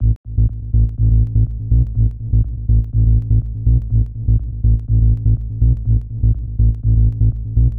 Loudest frequency 1262 Hz
• tech house bass samples - A - 123.wav
tech_house_bass_samples_-_A_-_123_ZWr.wav